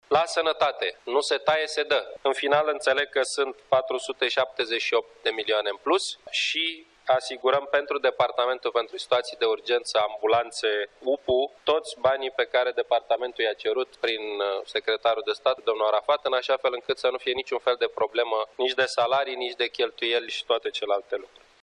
După cum a explicat premierul Victor Ponta la începutul şedinţei şi domeniul sănătăţii va avea mai multe fonduri :